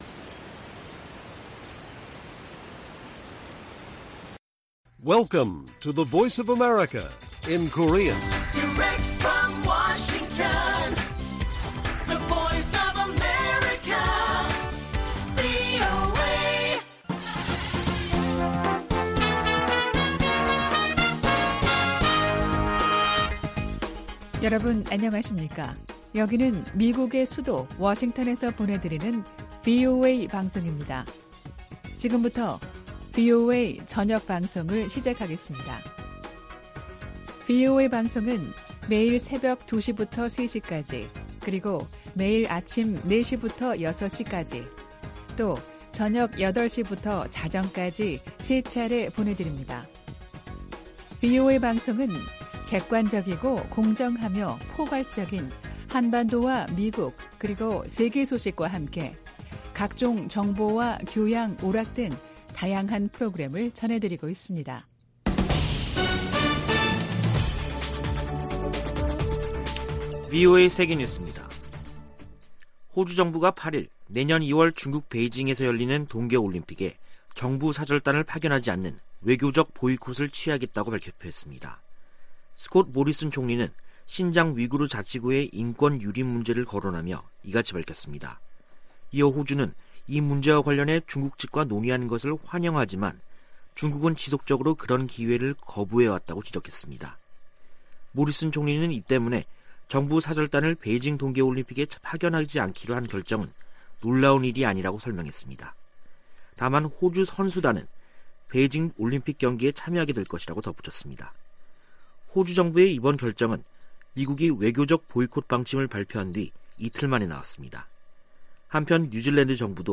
VOA 한국어 간판 뉴스 프로그램 '뉴스 투데이', 2021년 12월 8일 1부 방송입니다. 조 바이든 미국 행정부의 ‘외교적 보이콧’으로, 베이징 올림픽 무대를 활용한 한반도 종전선언의 현실화 가능성이 크게 낮아졌습니다. 올림픽 보이콧으로 북한 문제 관련 미-중 협력을 기대하기 어렵게 됐다고 전문가들이 지적했습니다. 문재인 한국 대통령은 종전선언에 국제사회 지지를 거듭 촉구하는 한편, 청와대는 올림픽 보이콧을 검토하지 않고 있다고 밝혔습니다.